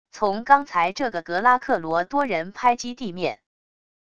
从刚才这个格拉克罗多人拍击地面wav音频